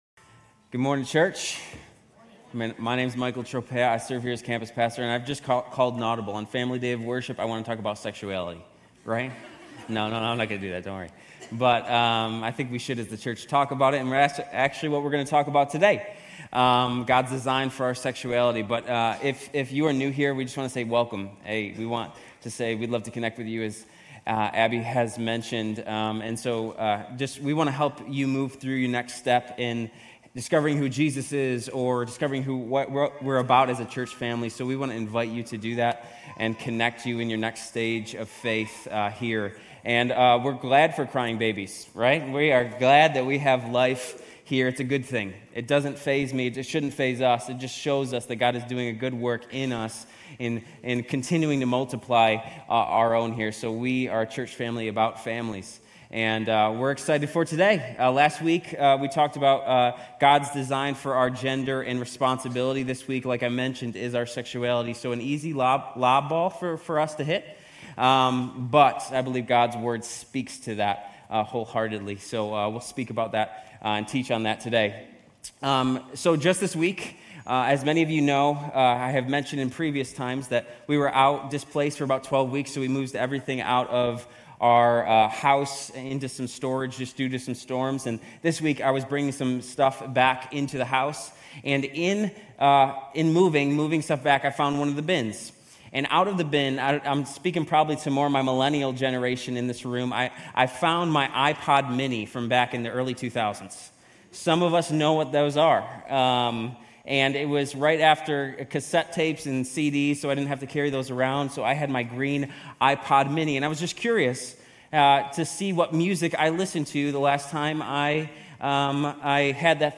Grace Community Church University Blvd Campus Sermons Genesis 1:26-28, 2:21-25, Rom 1:29-30 - Sexuality Sep 15 2024 | 00:34:37 1x 00:00 / 00:34:37 Subscribe Share RSS Feed Share Link Embed